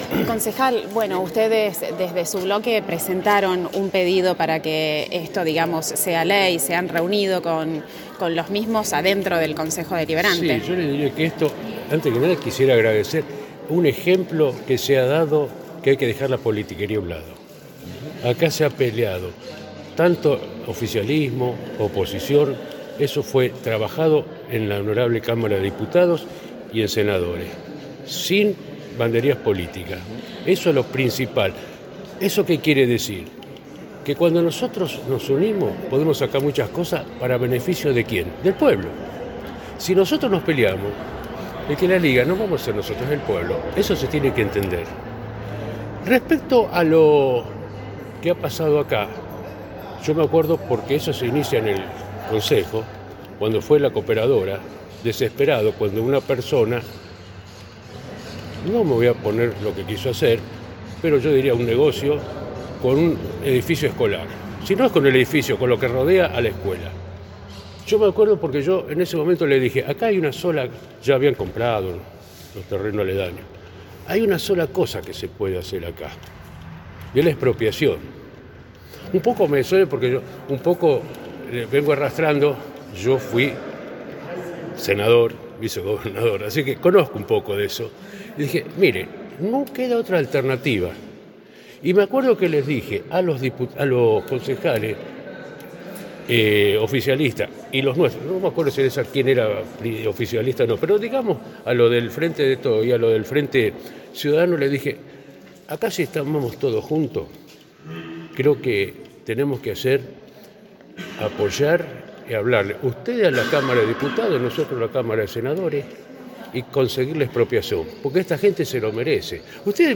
Convocada la prensa a la Escuela Campo Spagnolo tuvimos la posibilidad de hablar con autoridades Provinciales, Locales. la noticia es que la Escuela Campo Spagnolo podrá disponer del inmueble recuperado y las instalaciones en donde se encuentra.
Concejal Dr. Augusto Fischer